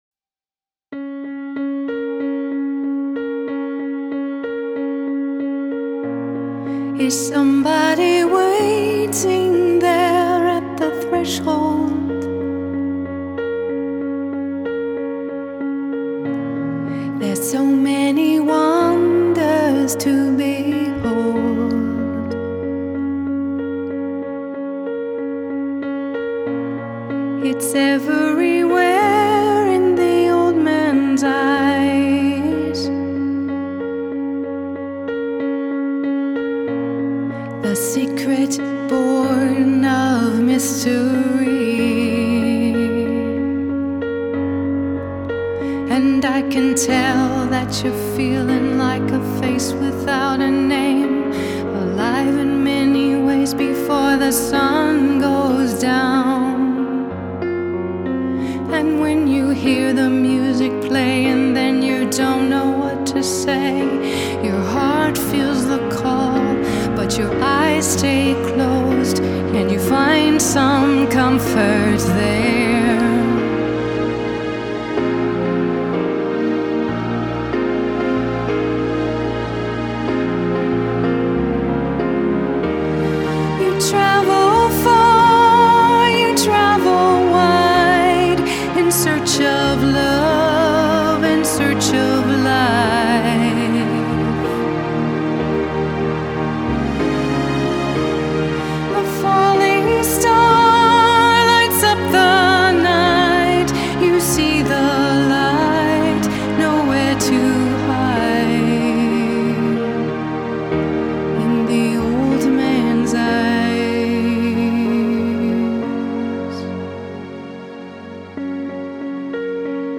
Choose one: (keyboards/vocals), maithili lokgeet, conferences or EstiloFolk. (keyboards/vocals)